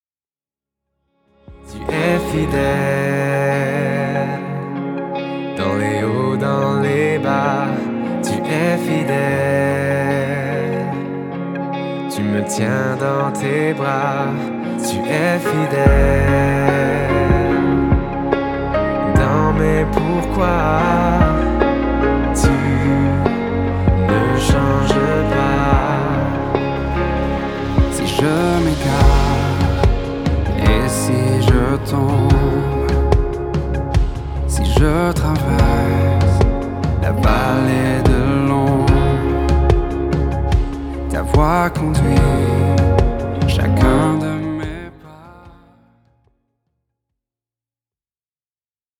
C'est aussi l'occasion d'un magnifique duo